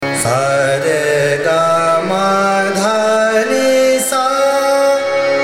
Raga
ArohaS r g M d N S’